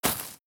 GravelStep4.wav